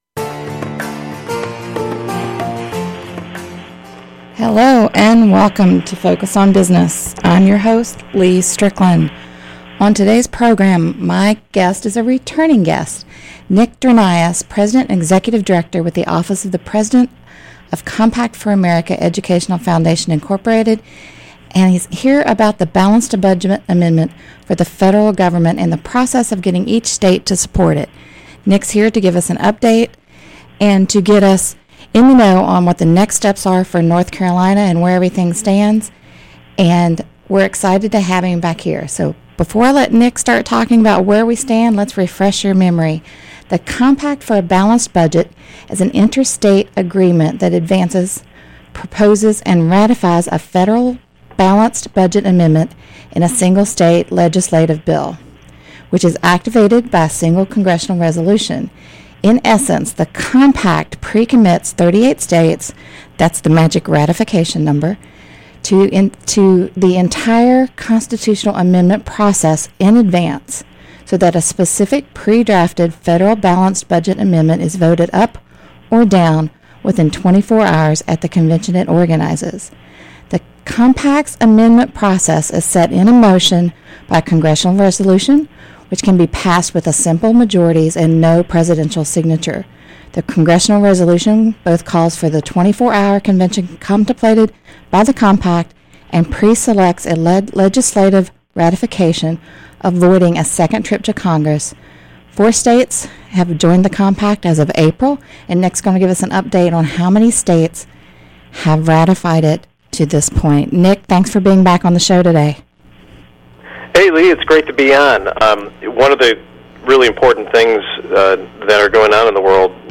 Focus on Business Radio Show